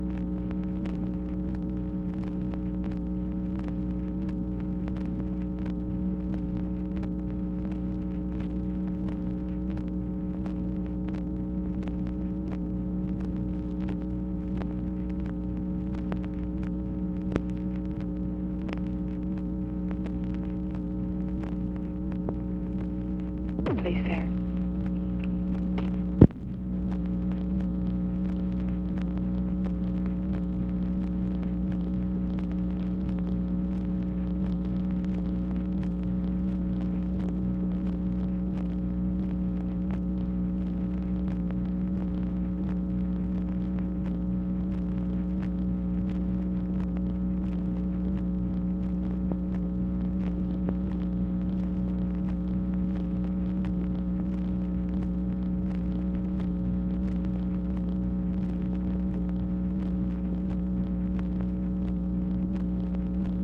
PRIMARILY MACHINE NOISE; OFFICE SECRETARY MAKES BRIEF COMMENT IN MIDDLE OF RECORDING
Conversation with OFFICE SECRETARY and MACHINE NOISE, January 20, 1967
Secret White House Tapes